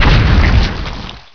EXPLO.WAV